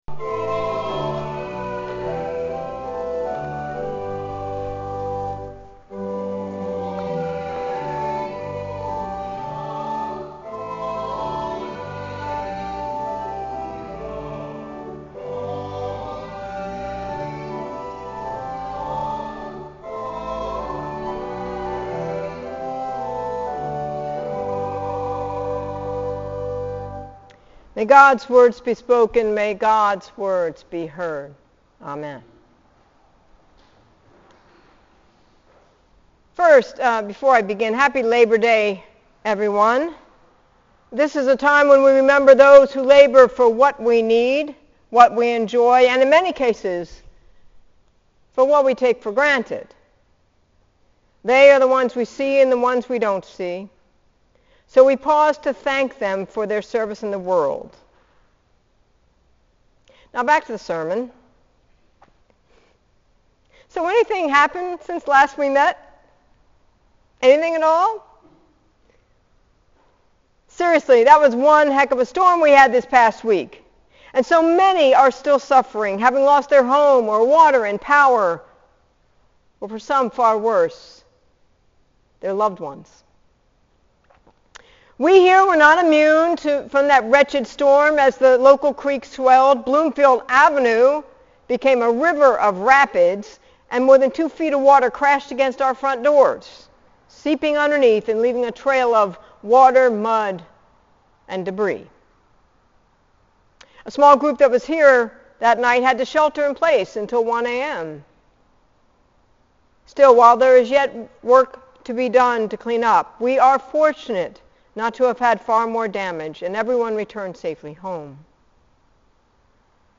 Now, back to the sermon…